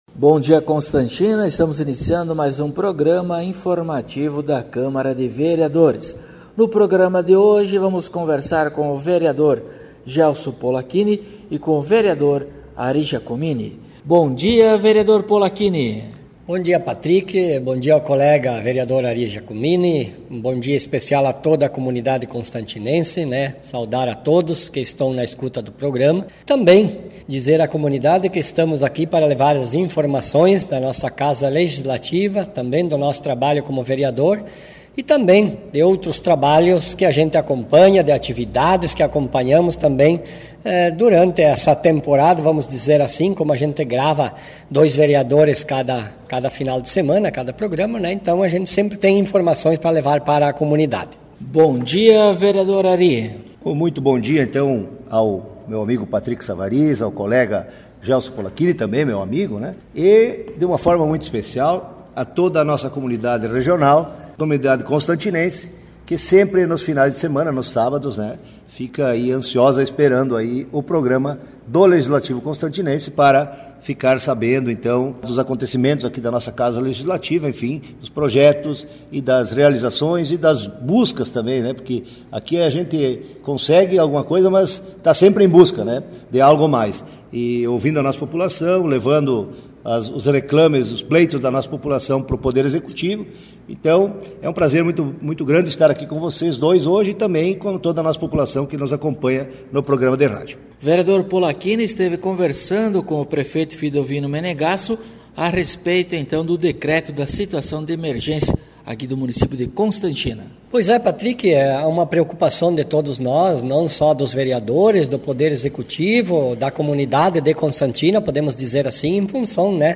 Acompanhe o programa informativo da câmara de vereadores de Constantina com o Vereador Gelso Polaquini e o Vereador Ari Giacomini.